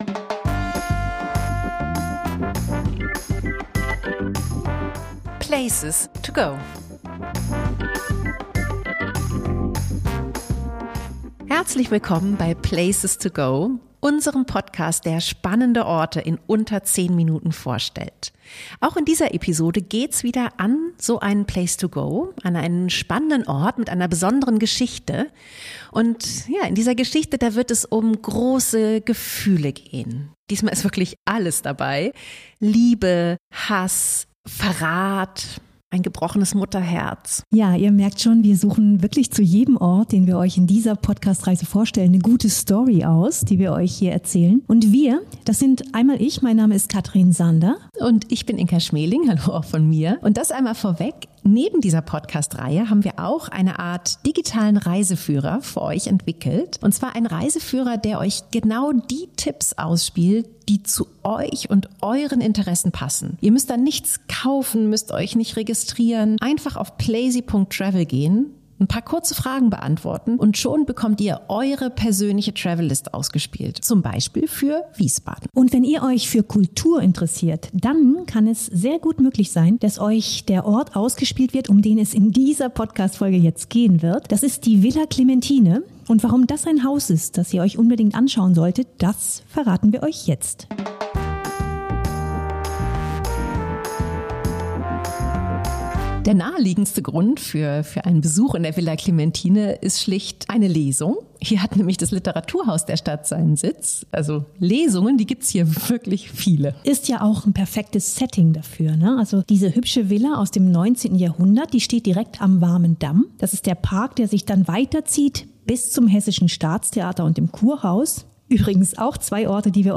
erzählen dir die beiden Hosts dieses plazy-Podcasts